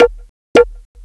PERC LOOP6-L.wav